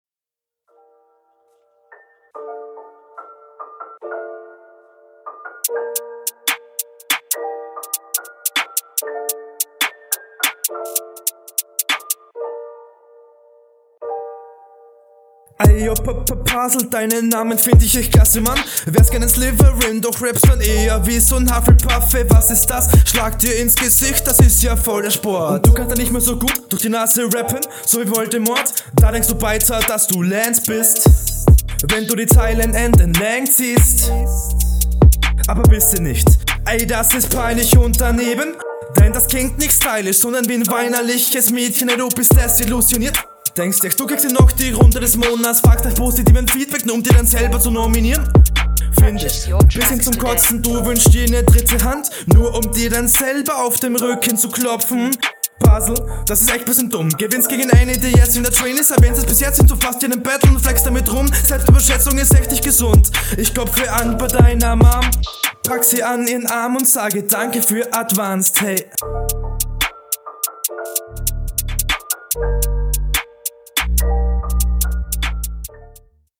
Der Stimmeinsatz ist okay, hier etwas zu drucklos, die Delivery geht klar, ist aber hier …
oh, Grime. und so ein trauriges Sample. hmm. find nicht, dass deine Stimme da so …